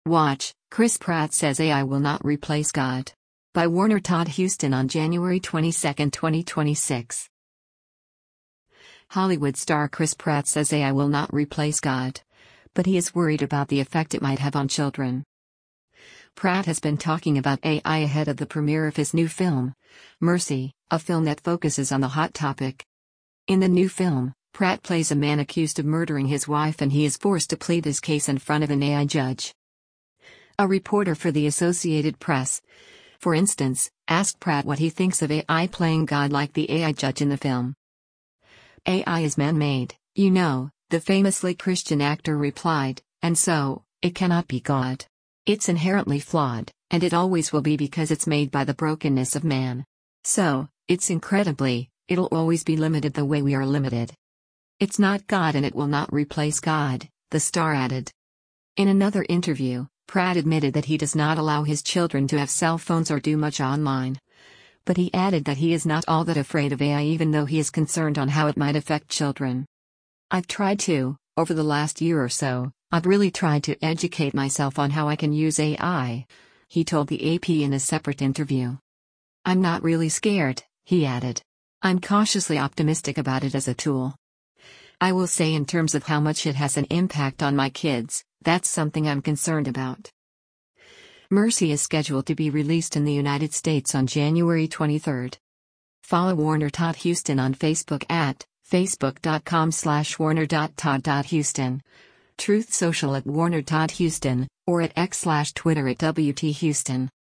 A reporter for the Associated Press, for instance, asked Pratt what he thinks of AI “playing God” like the AI judge in the film.